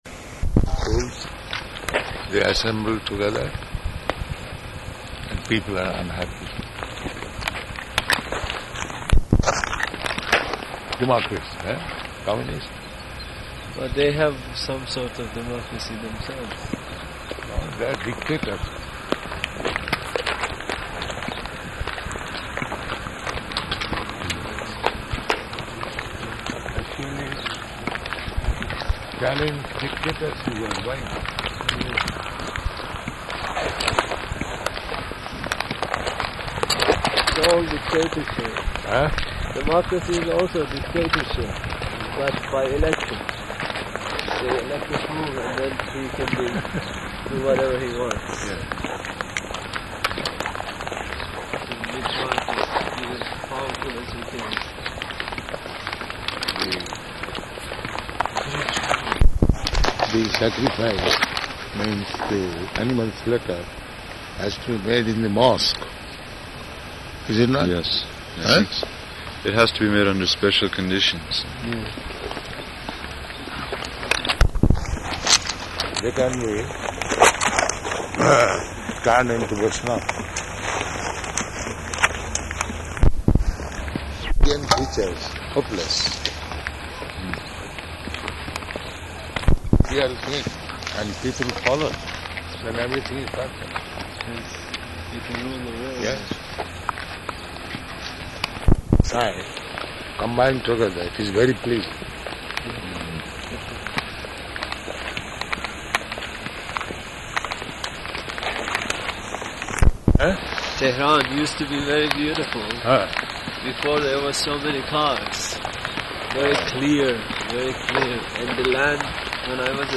Morning Walk
Type: Walk
Location: Tehran